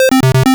retro_synth_beeps_groove_08.wav